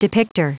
Prononciation du mot depicter en anglais (fichier audio)
Prononciation du mot : depicter